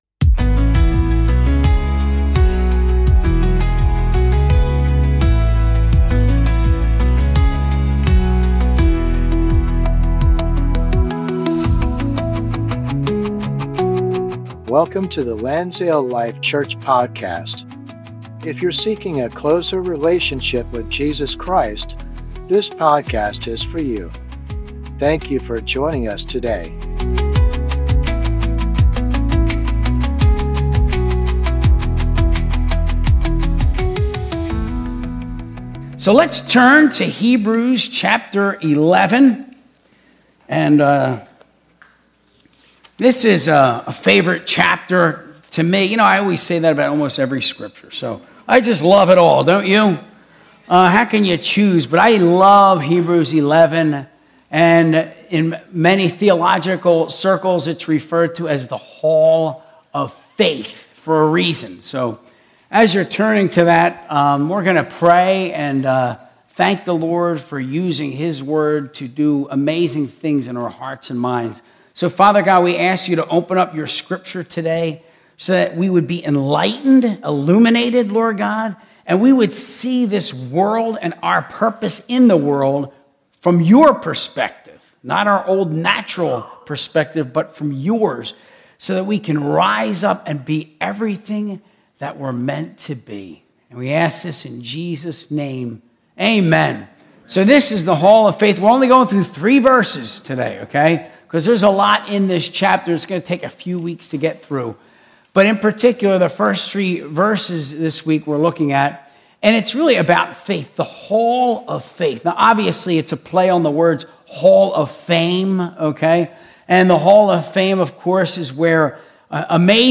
Sunday Service - 2025-11-02